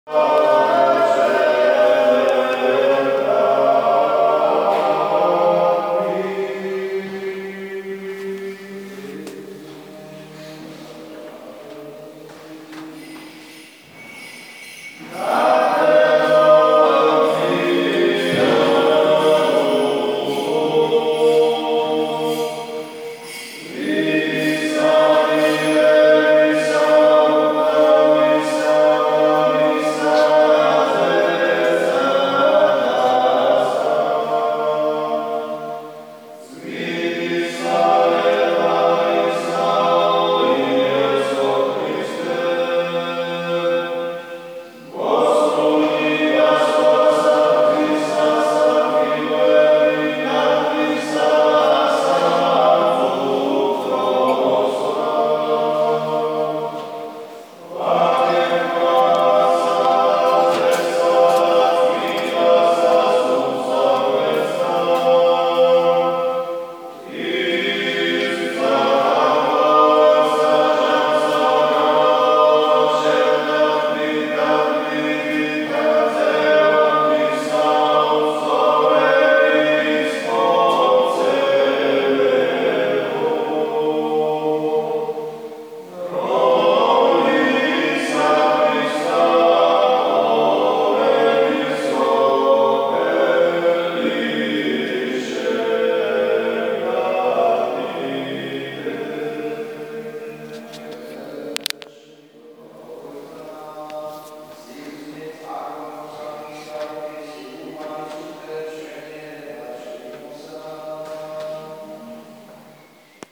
The path took me past the Mtatsminda Pantheon of Writers and Artists (“Oh look a church” I said to myself – there is no shortage of them in this devoutly Christian country) and this proved a pleasant break, a chance to listen to some great singing in the church and to fill my water bottle from the local spring.